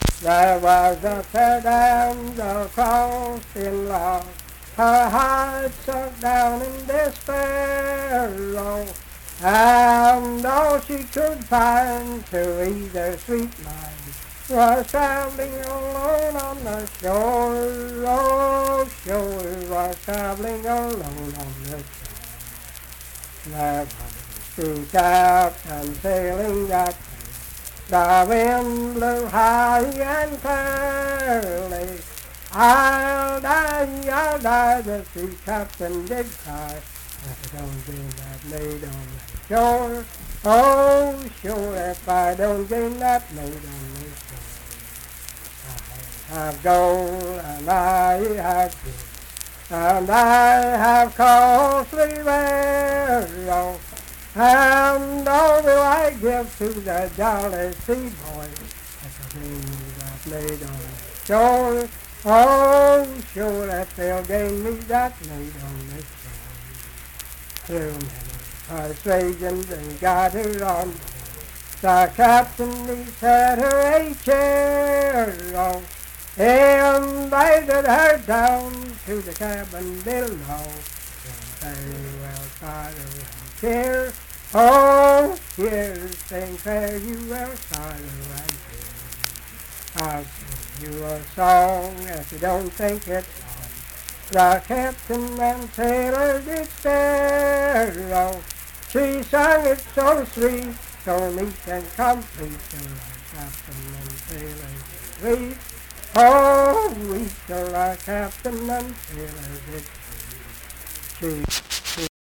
Unaccompanied vocal music
Verse-refrain 5(5w/R).
Performed in Ivydale, Clay County, WV.
Voice (sung)